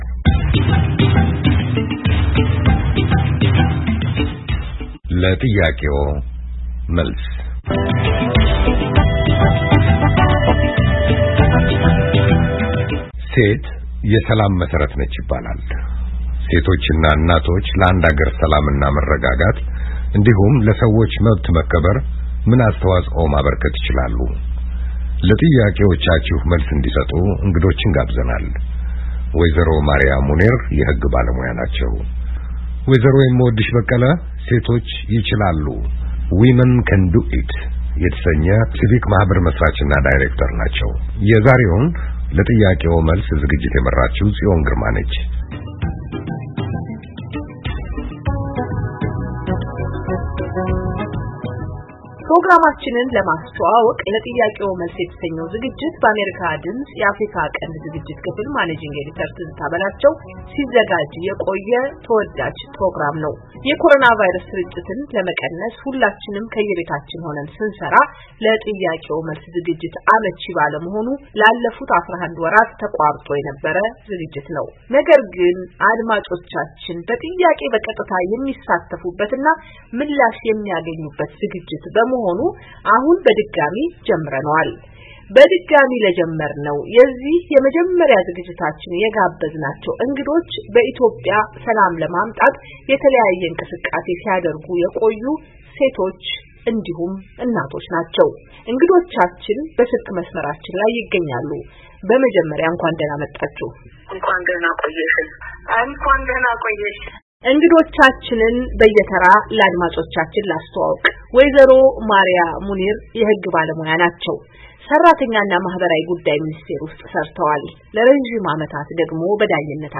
ሴቶች እና እናቶች ለአንድ አገር ሰላም እና መረጋጋት እንዲሁም ለሰዎች መብት መከበር ምን አስተዋፆ ማበርከት ይችላሉ? በጥያቄዎ መልስ ዝግጅታችን እንግዶችን ጋብዘን አወያይተናል።...